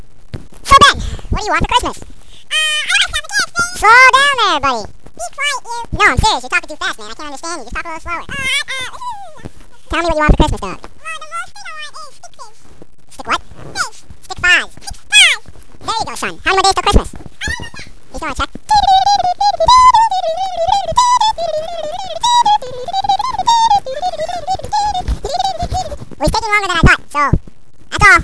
It is suspected that this chipmunk is indeed the chipmunk in the audio clips, probably the one with the lower voice.